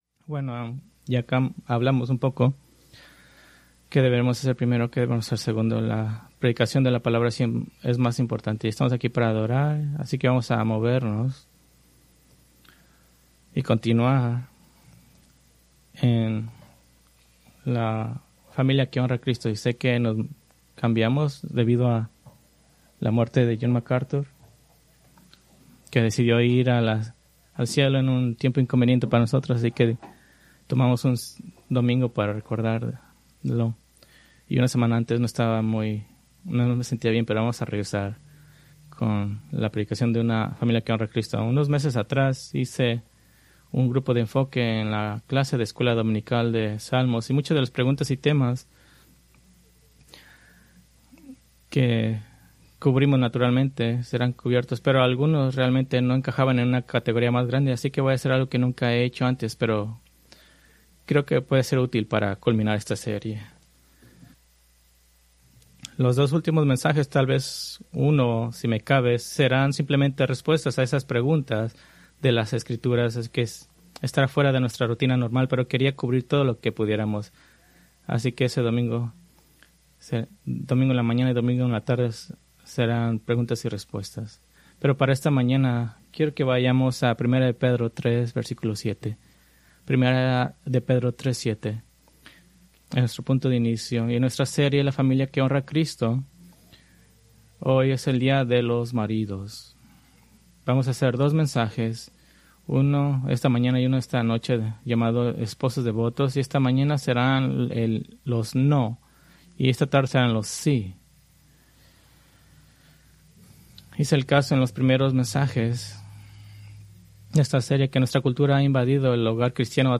Preached August 3, 2025 from Escrituras seleccionadas